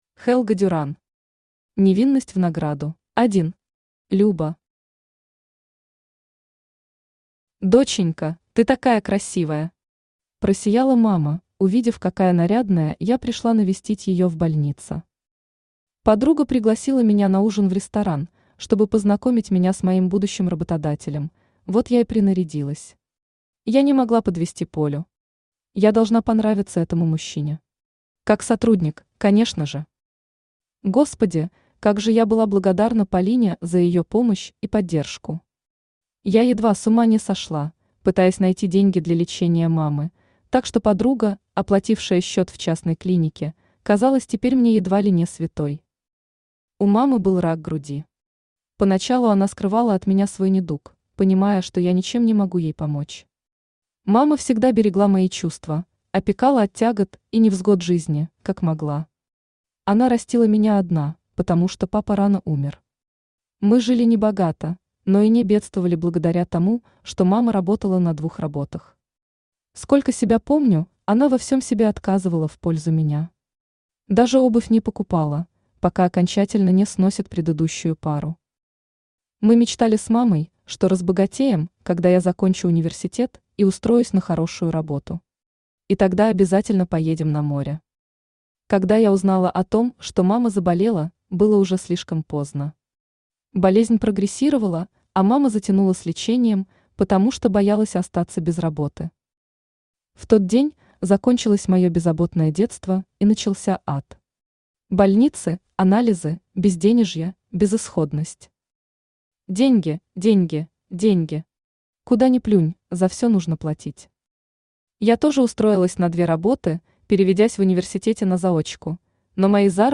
Аудиокнига Невинность в награду | Библиотека аудиокниг
Aудиокнига Невинность в награду Автор Helga Duran Читает аудиокнигу Авточтец ЛитРес.